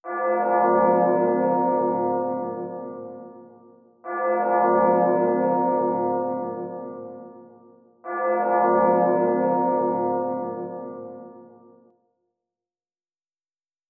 bell.wav